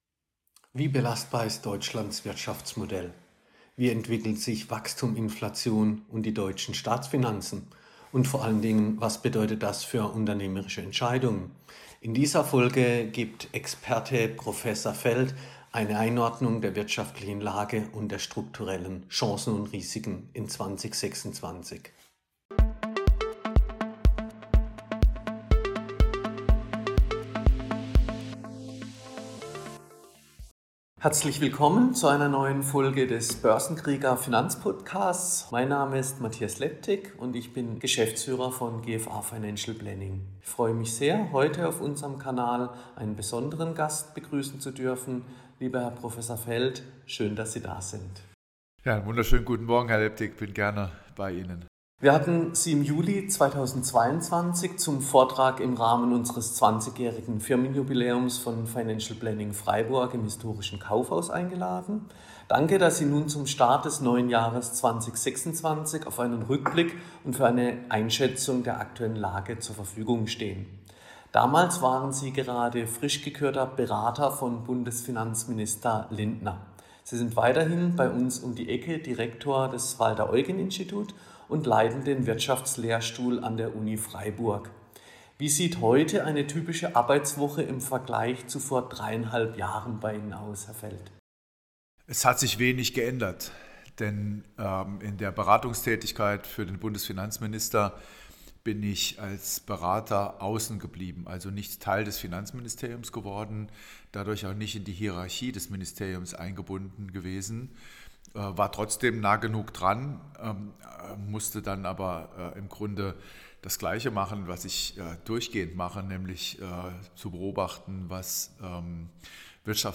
Schwaches Wachstum, hohe Schulden, neue Weltordnung – wie belastbar ist Deutschlands Wirtschaftsmodell? | Interview mit Prof. Lars P. Feld ~ Börsenkrieger Podcast
Prof-_Feld-Interview_komplett.mp3